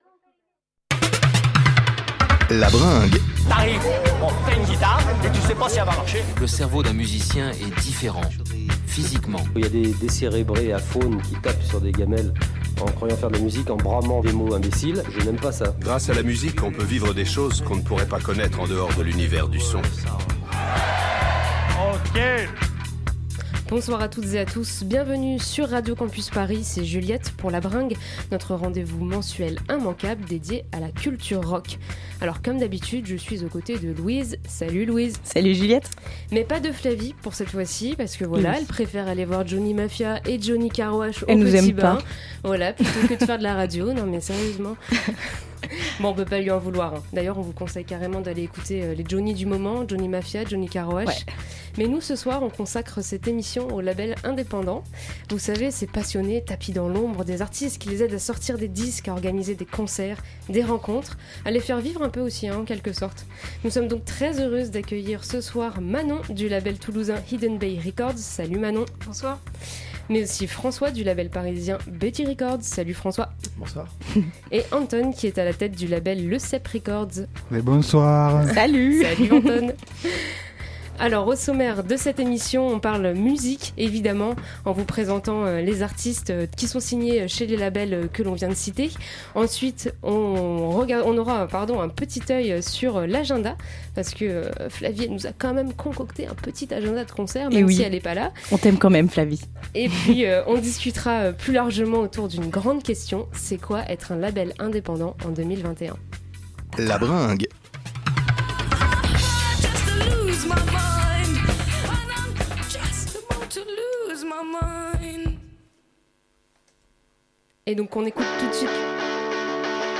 Type Musicale Découvertes musicales Courants Alternatifs Pop & Rock